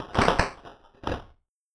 locked_door.wav